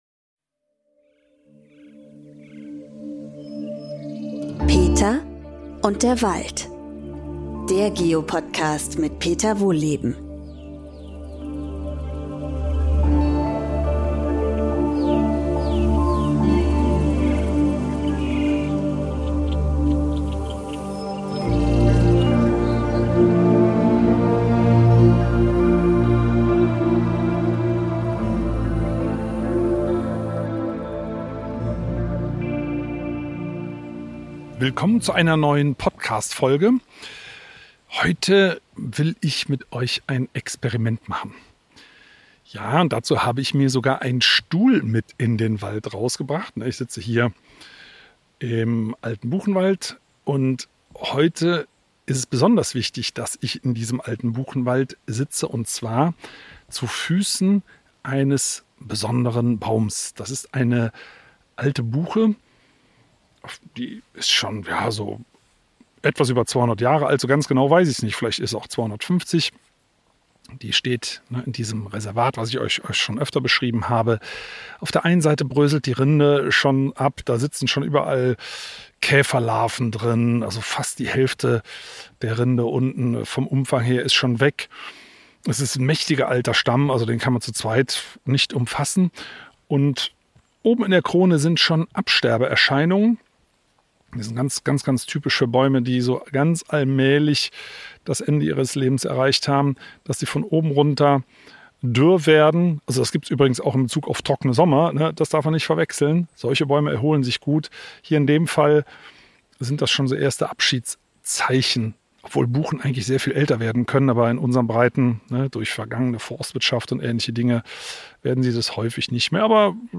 Peter liest aus seinem neuen Buch vor und nimmt euch mit auf eine emotionale Reise: In "Buchenleben" erzählt er die spannende Lebensgeschichte einer über 200 Jahre alten Buche – aus "ihrer" eigenen Baum-Perspektive.